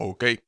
worker_ack2.wav